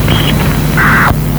Опознать сигнал